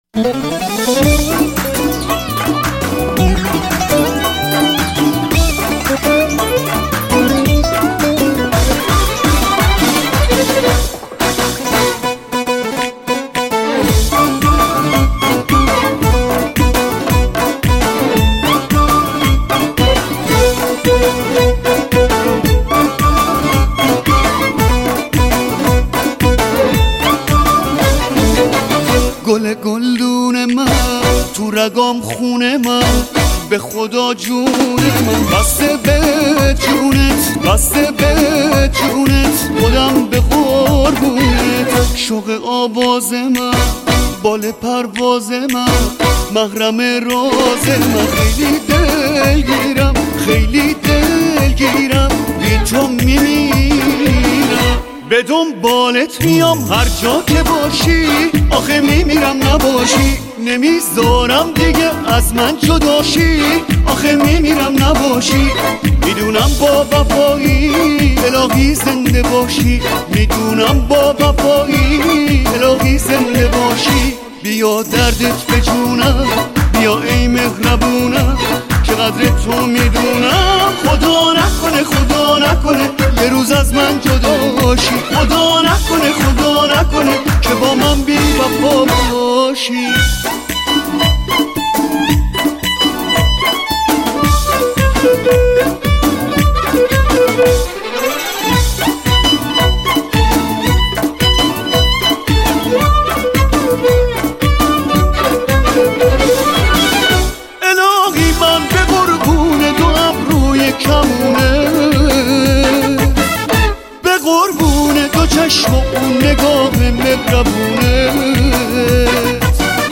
سبک این موزیک شاد می باشد